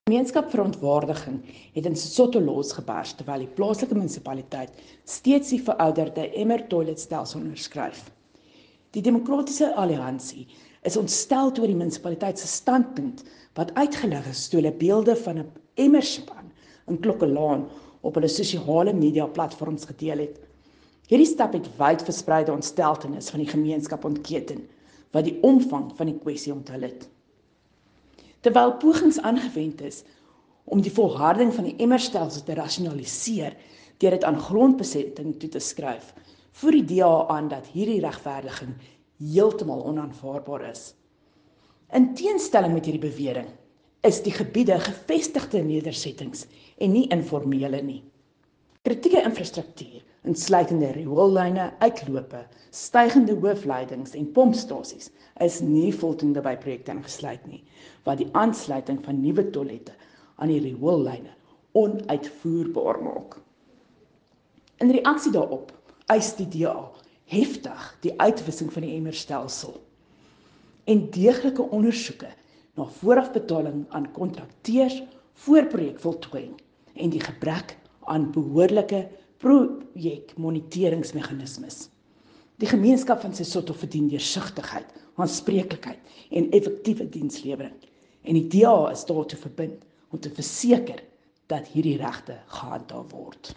Afrikaans soundbites by Cllr Riette Dell.